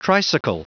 Prononciation du mot tricycle en anglais (fichier audio)
Prononciation du mot : tricycle